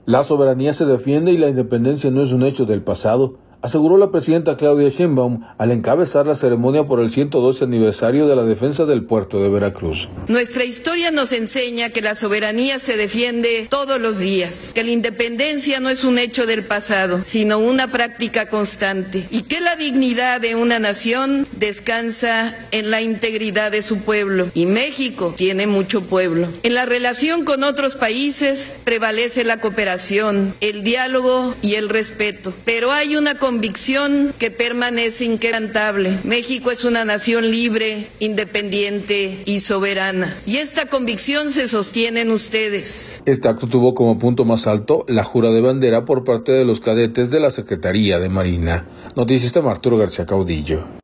La soberanía se defiende y la independencia no es un hecho del pasado, aseguró la presidenta Claudia Sheinbaum, al encabezar la ceremonia por el 112 Aniversario de la Defensa del Puerto de Veracruz.